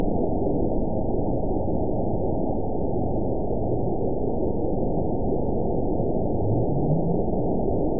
event 920514 date 03/28/24 time 14:23:59 GMT (1 year, 1 month ago) score 9.58 location TSS-AB01 detected by nrw target species NRW annotations +NRW Spectrogram: Frequency (kHz) vs. Time (s) audio not available .wav